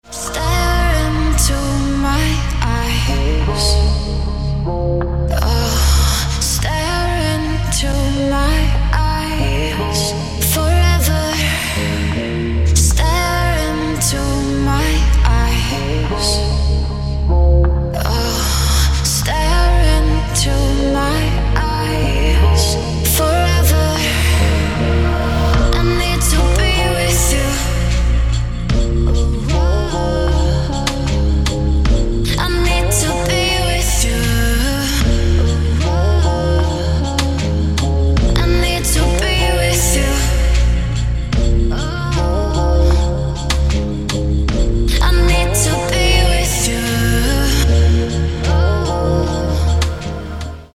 • Качество: 160, Stereo
женский вокал
Electronic
спокойные
ballads
Chill
Melodic
romantic
vocal